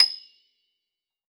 53q-pno27-D6.wav